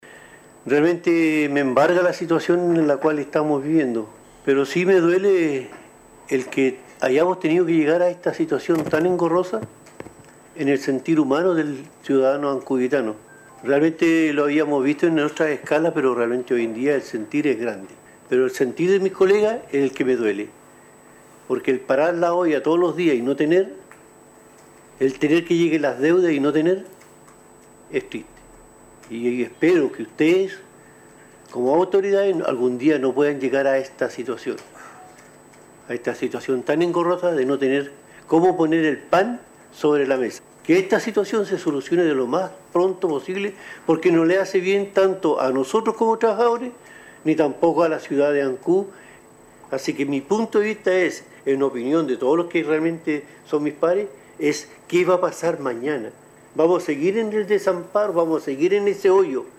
Todos estos alcances sobre este problema por el que atraviesan los 29 funcionarios no reconocidos por la corporación municipal fueron tratados en la sesión de concejo realizada este lunes en Ancud y que correspondió al tercer llamado para constituir la reunión, luego que en las dos anteriores, no hubo Quorum para iniciarla.